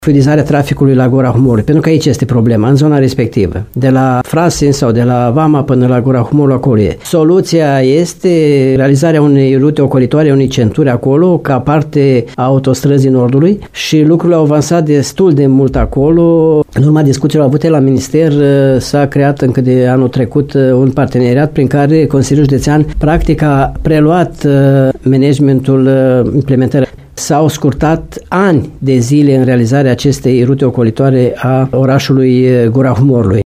El a declarat postului nostru că administrația județeană a preluat managementul implementării investițiilor, pentru a grăbi realizarea lor.